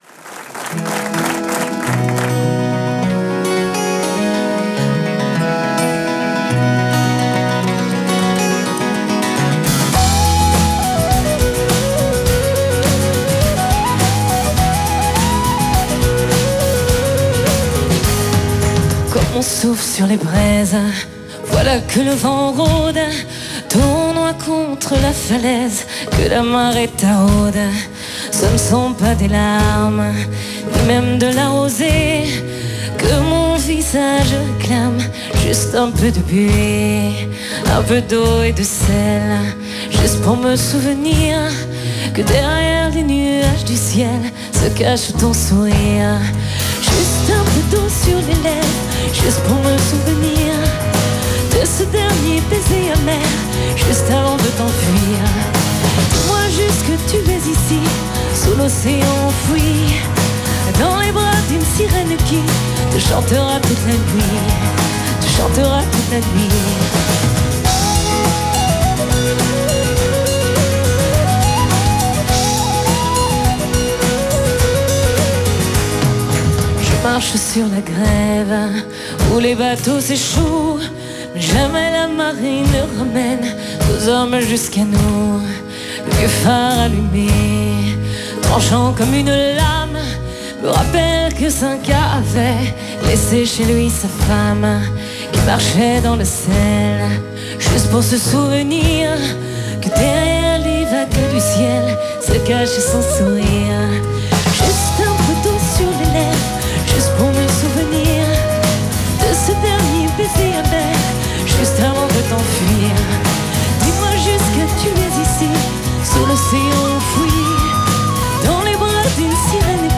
je suis rassurée le son est bon à la radio.